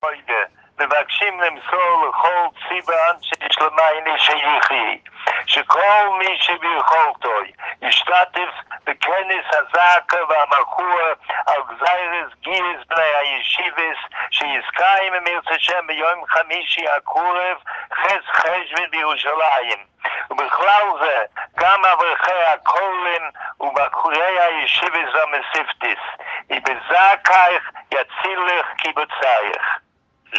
פרסם הודעה מוקלטת לחסידים בכל רחבי הארץ, בקריאה לאברכי ובחורי הישיבות של החסידות להגיע לעצרת.